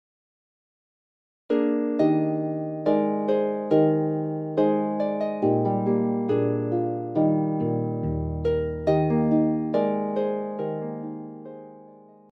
arranged for solo lever and pedal harp